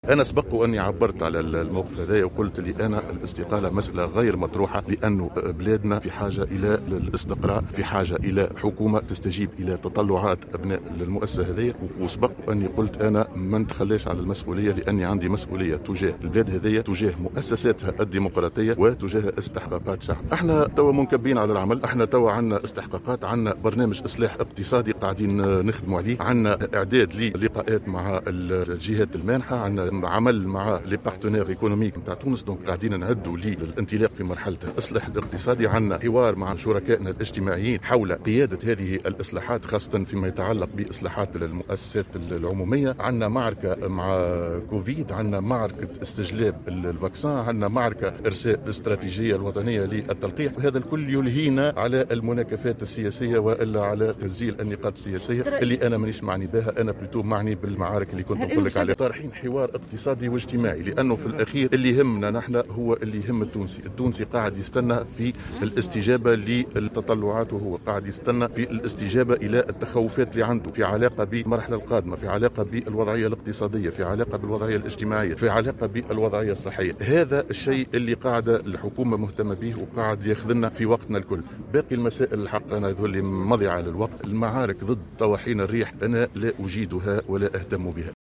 تصريح لمراسلنا